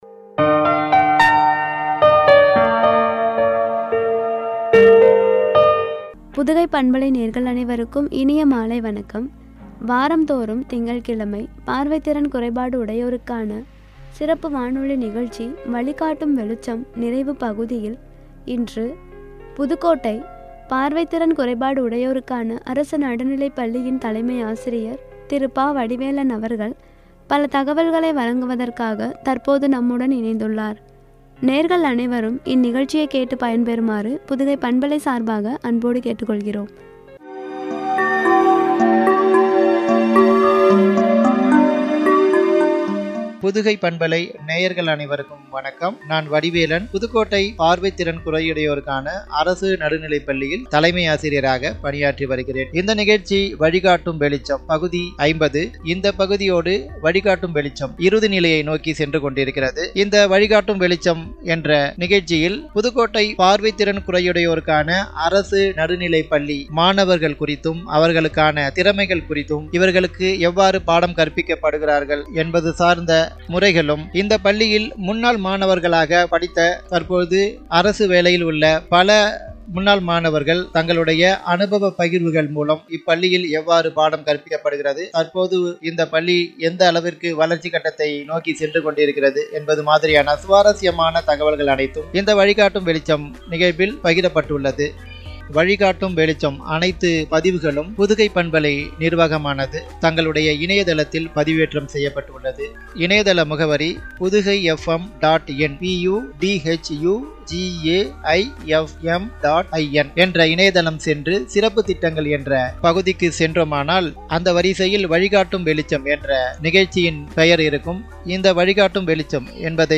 பார்வை திறன் குறையுடையோருக்கான சிறப்பு வானொலி நிகழ்ச்சி
குறித்து வழங்கிய உரையாடல்.